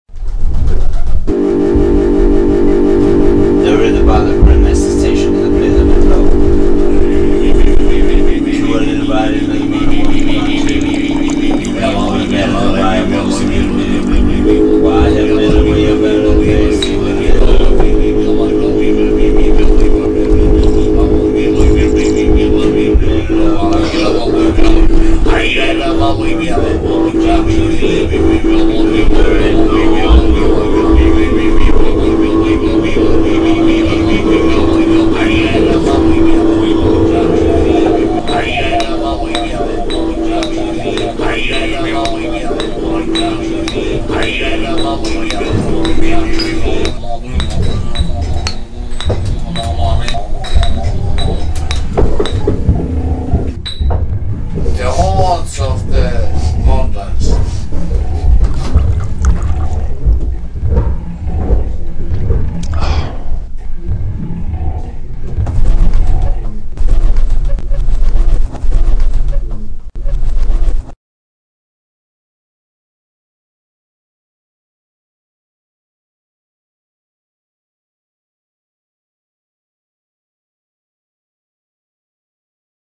Regardless of the place of the event, at the peak of drinking some men become susceptible to visions which involve the apparition of a mysterious Hoard of the Mountains, as recorded on this track, on New Year’s Eve 2001. As the situation was a German-Finnish meeting, this extraordinary visionary moment is worded in English.